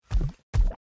foot_1.wav